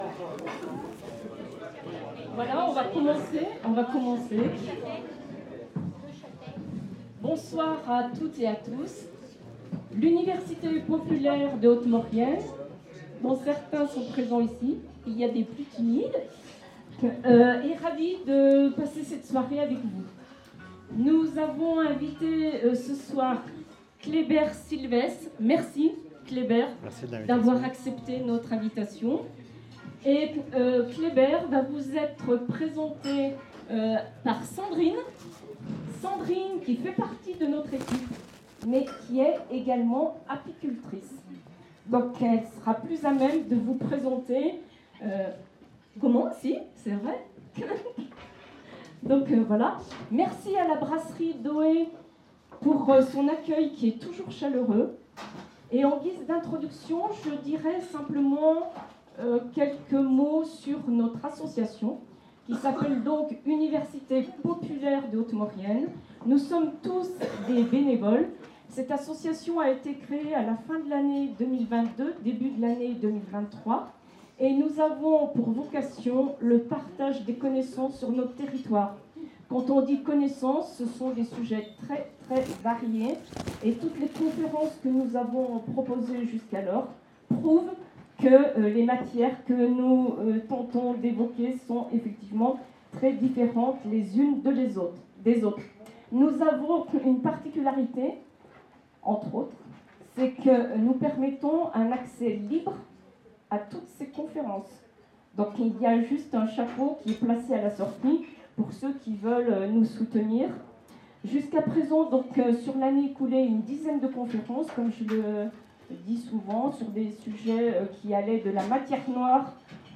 Conférence du 22 Mars 2024 organisée par l’Université Populaire de Haute Maurienne.